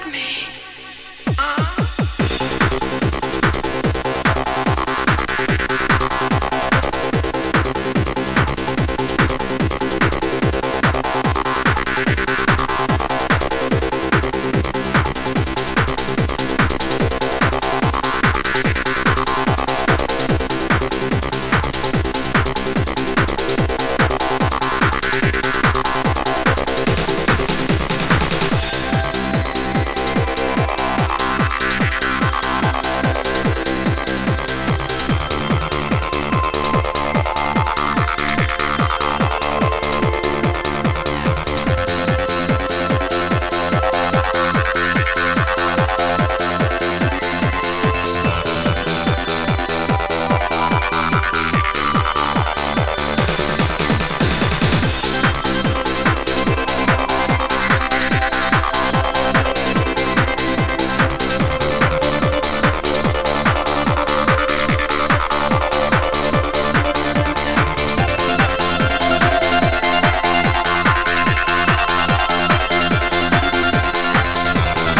多幸感溢れるTrance